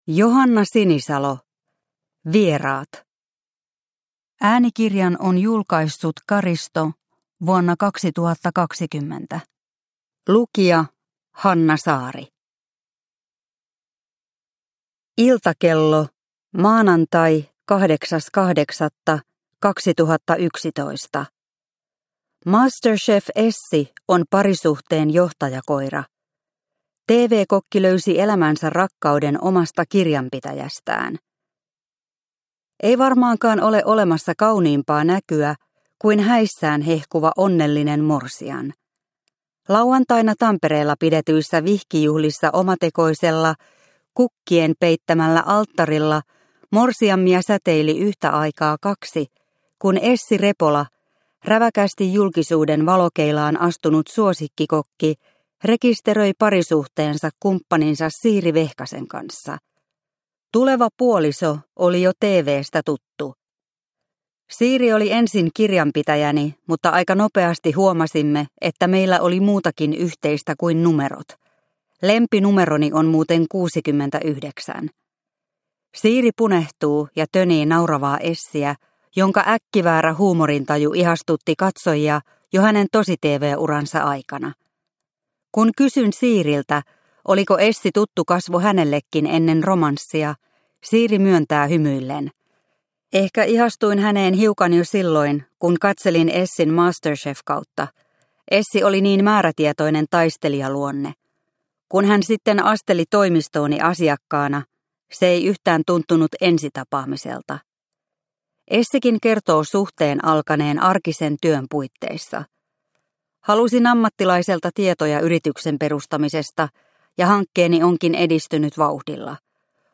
Vieraat – Ljudbok – Laddas ner